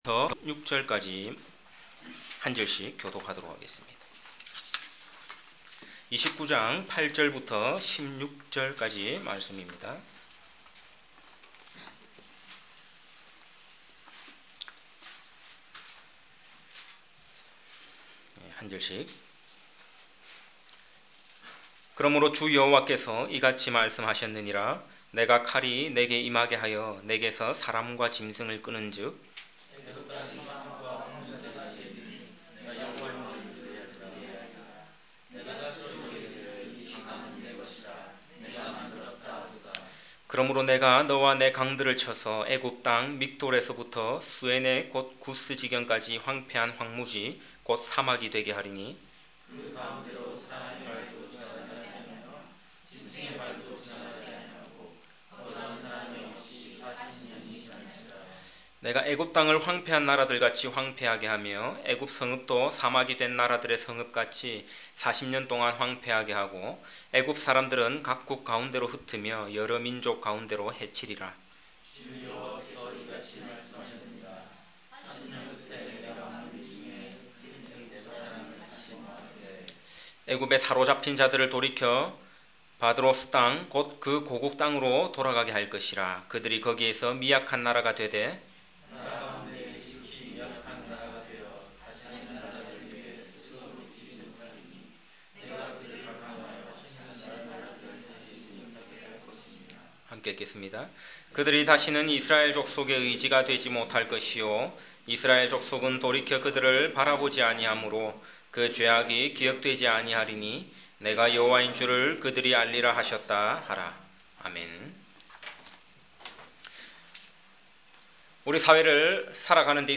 에스겔 강해(30) [29:8-16] 설교 듣기